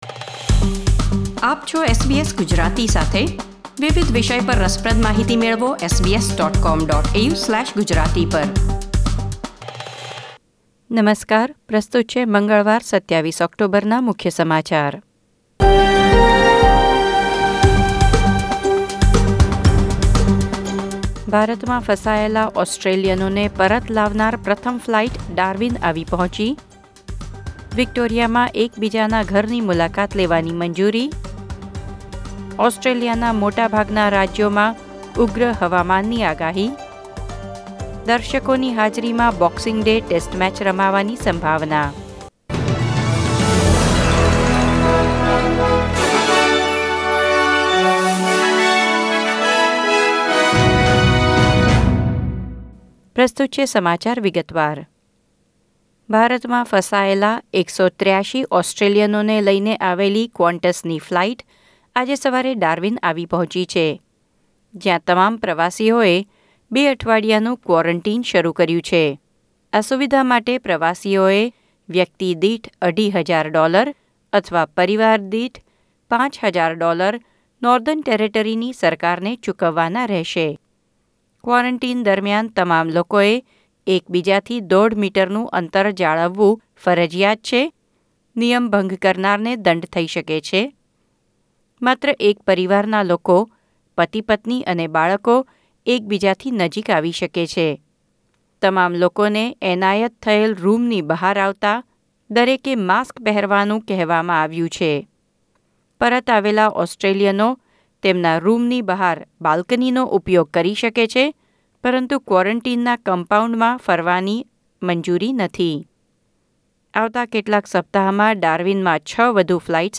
SBS Gujarati News Bulletin 27 October 2020